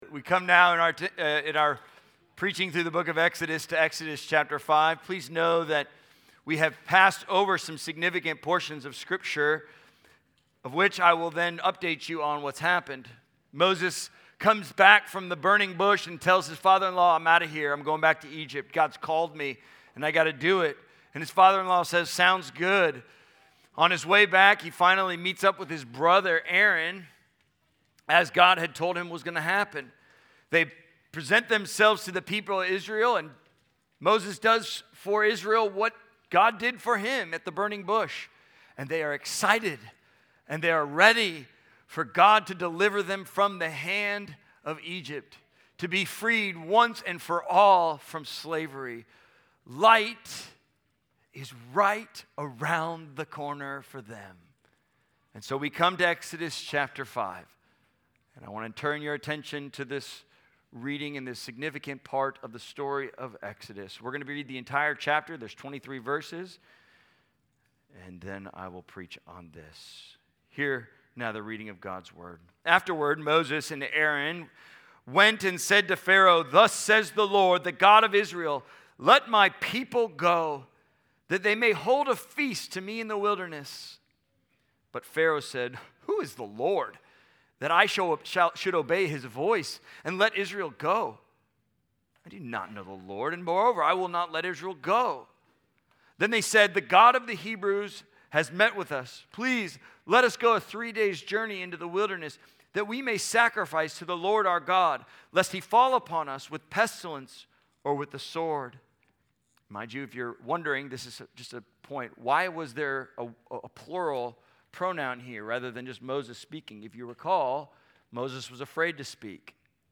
Darkness Before The Light (Exodus 5) | Central Hope Church Little Rock : Be Loved and Love